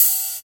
81 OP HAT 2.wav